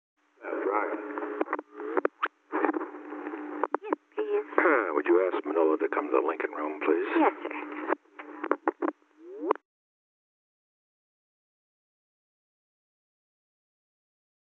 • White House operator
Location: White House Telephone
The President talked with the White House operator.
The President conferred with an unknown person.